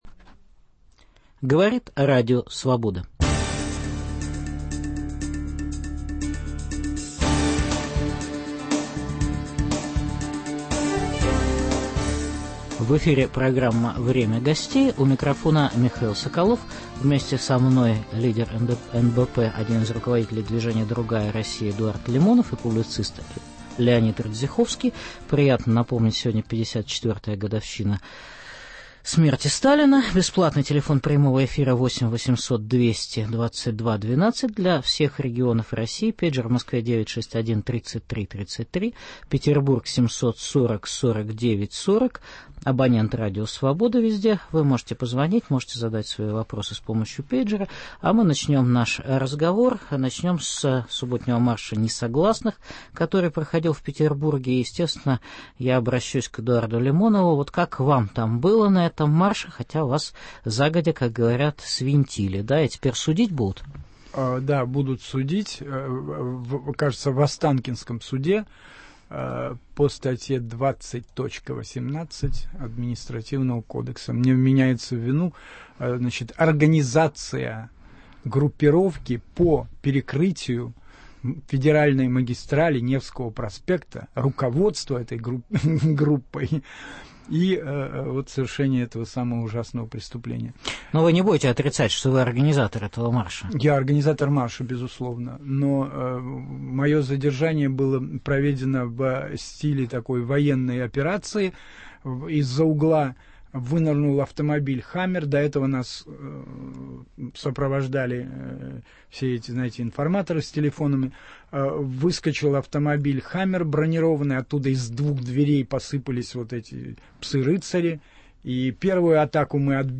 Итоги питерского «Марша несогласных» обсудят публицист Леонид Радзиховский и один из лидеров движения «Другая Россия» Эдуард Лимонов.